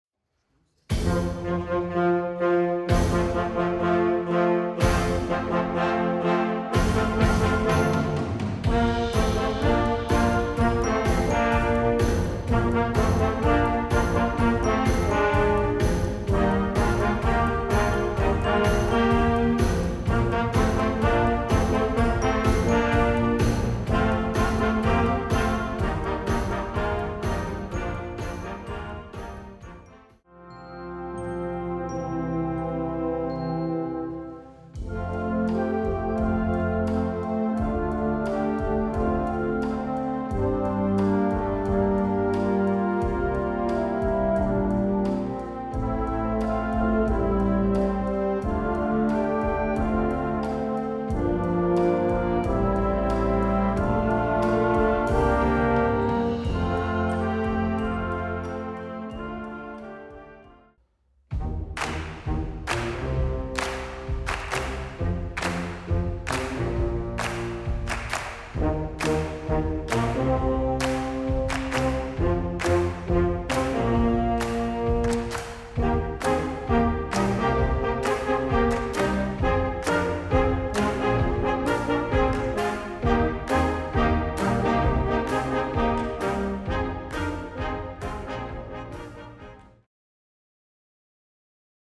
Gattung: für Jugendblasorchester
Besetzung: Blasorchester
Dies ist ein sehr einfaches Stück
instrumentiert für variable Besetzung